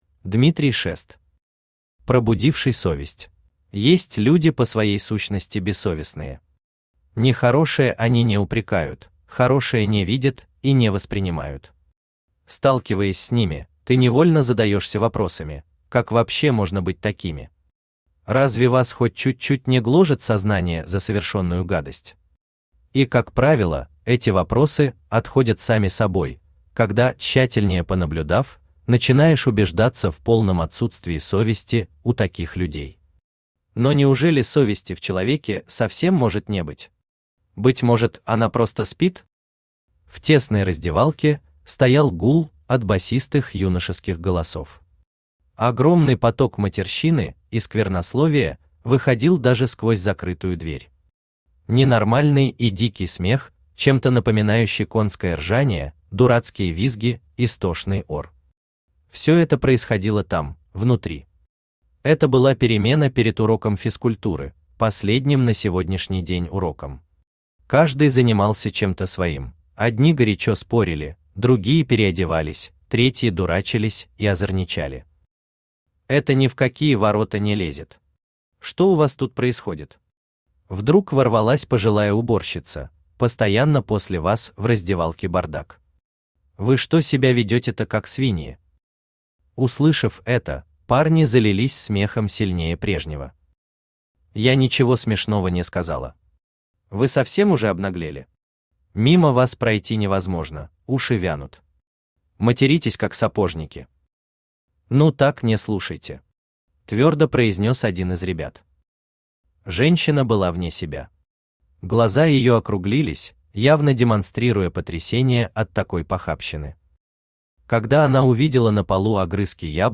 Аудиокнига Пробудивший совесть | Библиотека аудиокниг
Aудиокнига Пробудивший совесть Автор Дмитрий Шест Читает аудиокнигу Авточтец ЛитРес.